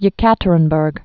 (yĭ-kătər-ən-bûrg) or E·ka·te·rin·burg (ĭ-kăt-)